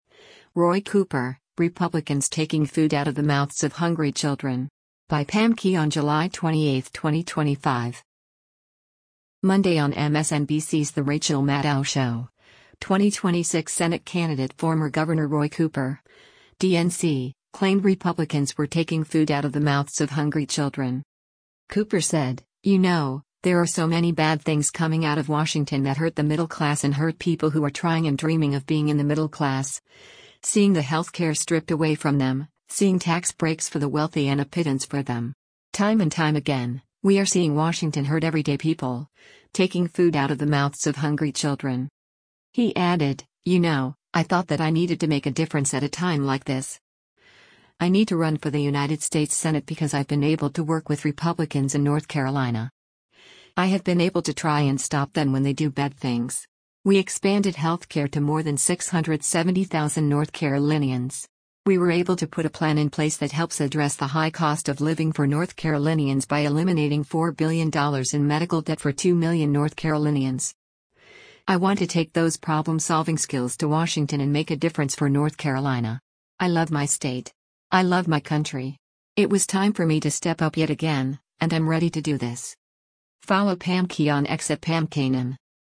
Monday on MSNBC’s “The Rachel Maddow Show,” 2026 Senate candidate former Gov. Roy Cooper (D-NC) claimed Republicans were “taking food out of the mouths of hungry children.”